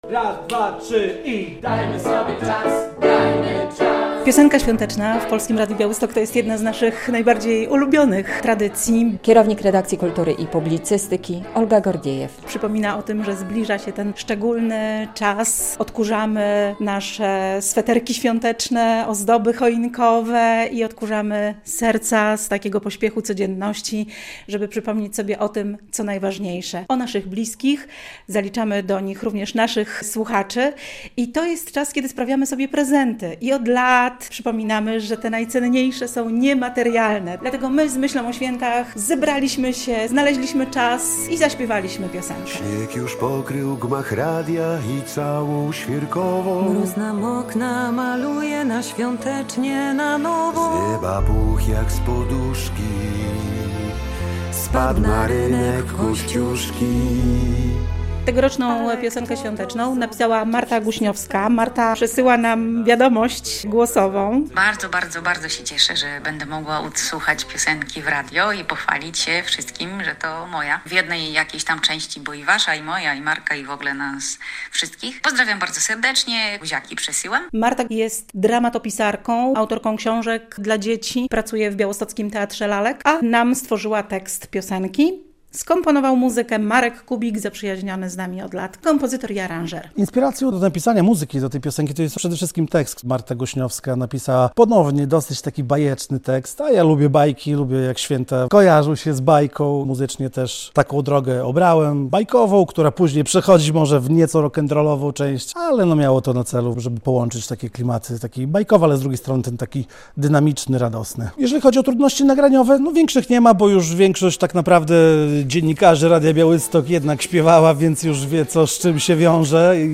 Jak powstawała nasza radiowa piosenka - relacja